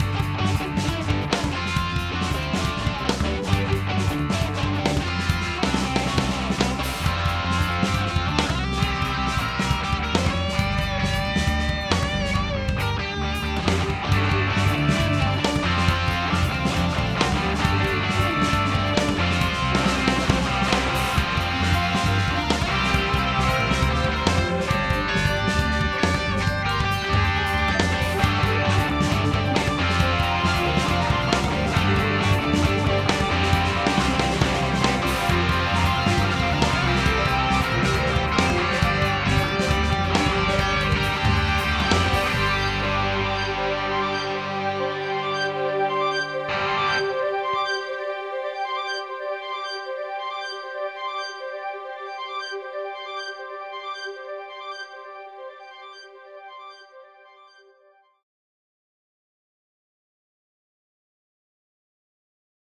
Studio test - 1 - August 2011